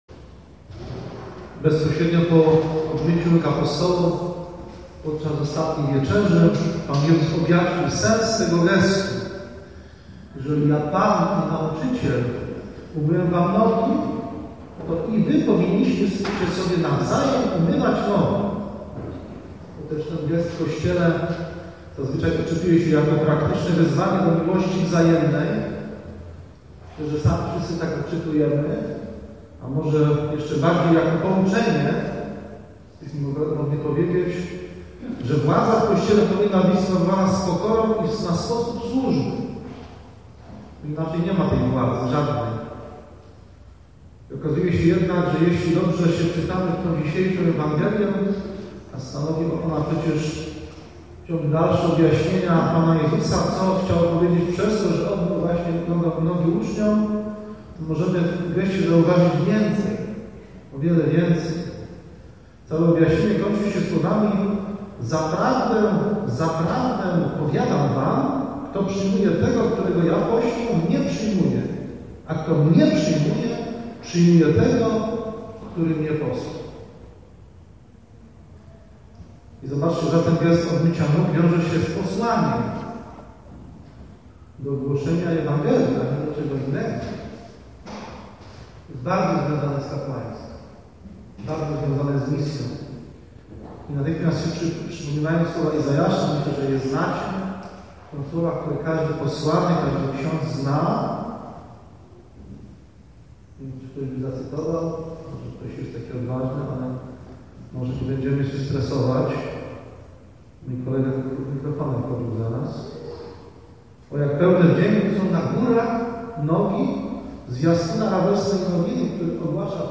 21 kwietnia 2016 – Msza św. – homilia